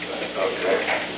On October 26, 1971, President Richard M. Nixon and unknown person(s) met in the President's office in the Old Executive Office Building at an unknown time between 1:51 pm and 2:49 pm. The Old Executive Office Building taping system captured this recording, which is known as Conversation 303-008 of the White House Tapes.